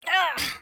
sfx_action_hurt_kid_01.wav